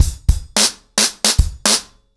BlackMail-110BPM.23.wav